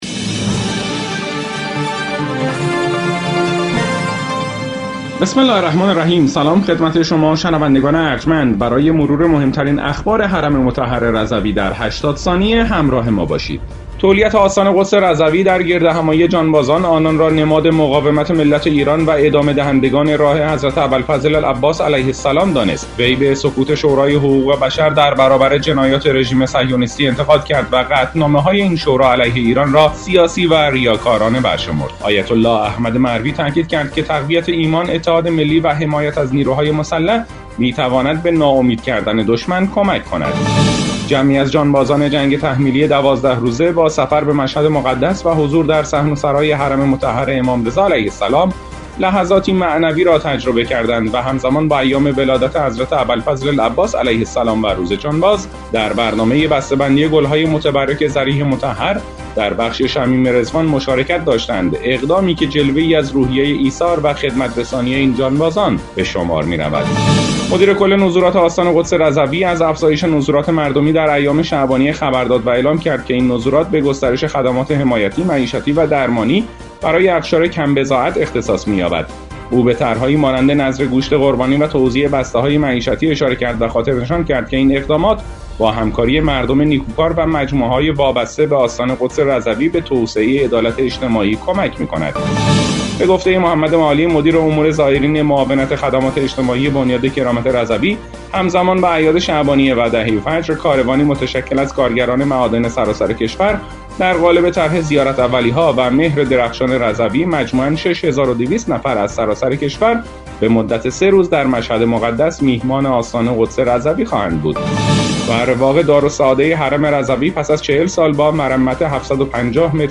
برچسب ها: رادیو رضوی بسته خبری رادیو رضوی رادیو